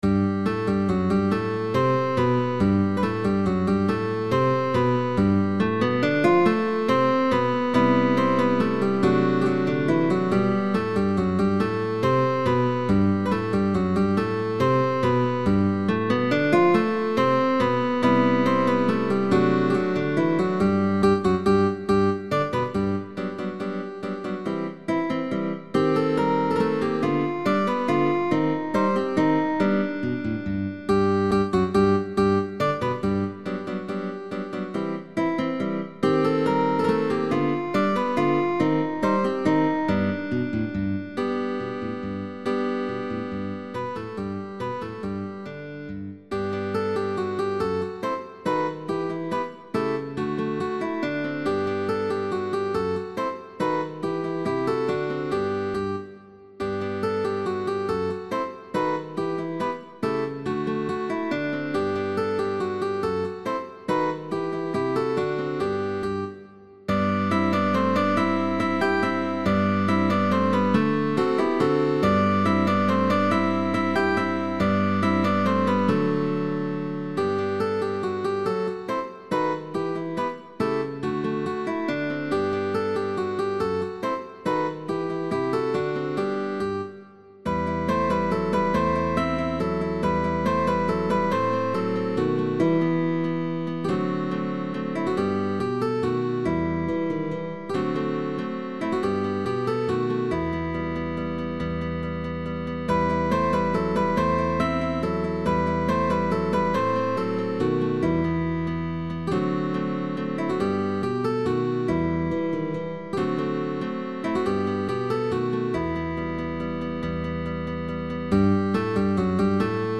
Stacatto.
Bass optional.
GUITAR QUARTET